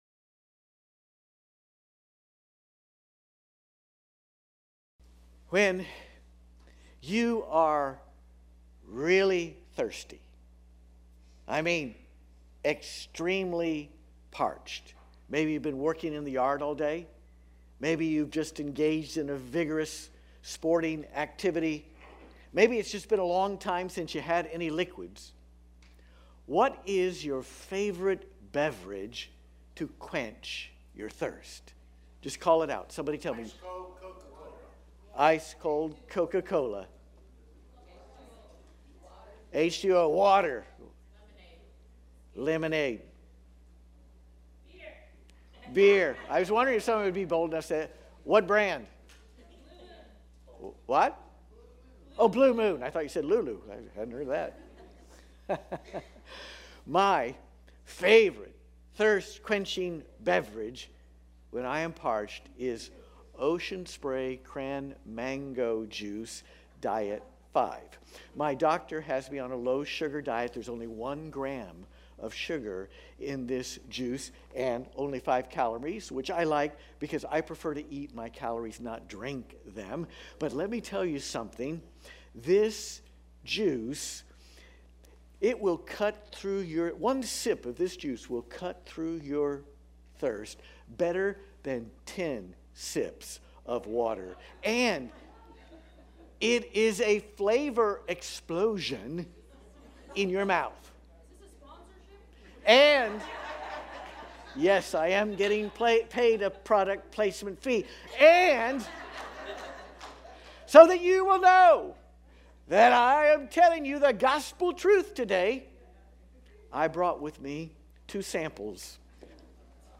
LIVE Stream Replay